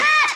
SG - Vox 8.wav